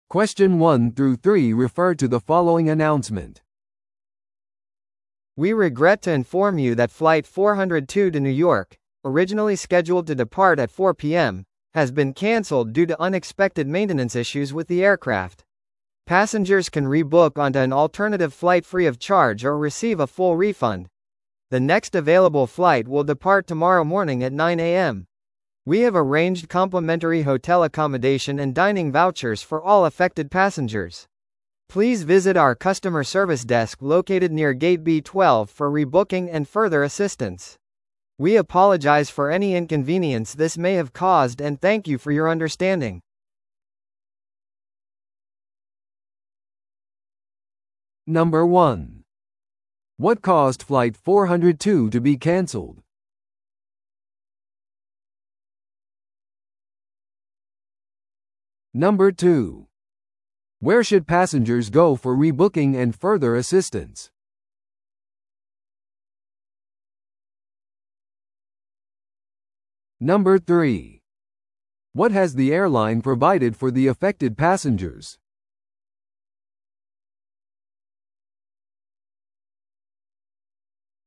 TOEICⓇ対策 Part 4｜欠航したフライトの案内 – 音声付き No.071